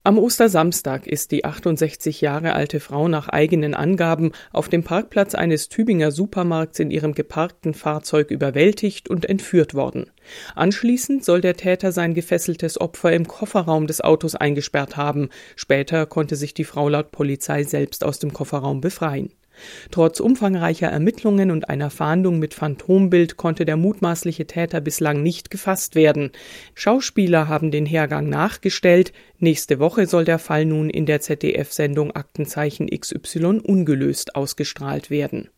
Moderator von "Aktenzeichen XY", Rudi Cerne: Was an diesem Fall besonders ist